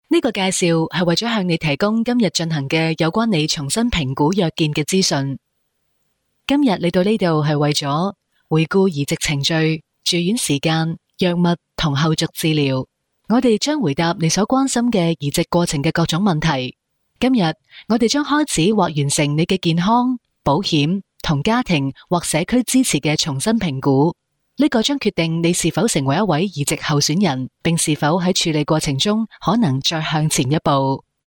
Female Professional Voice Over Talent | VoicesNow Voiceover Actors